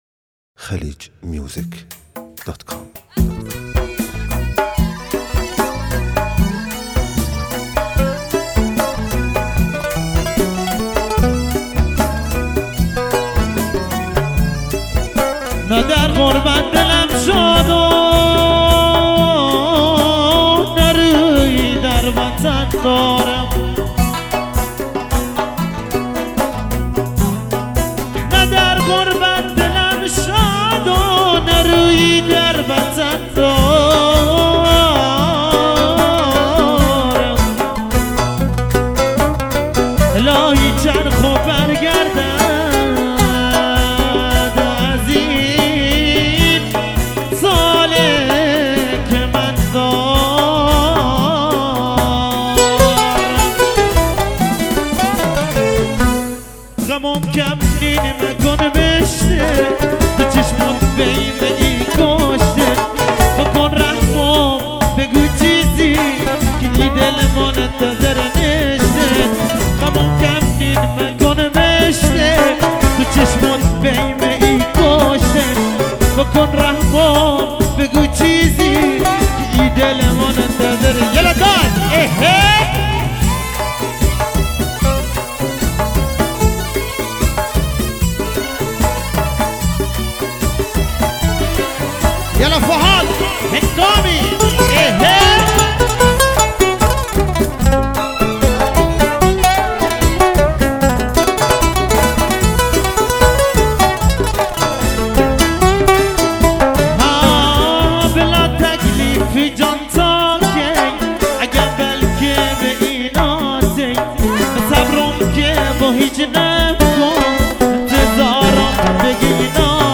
بندری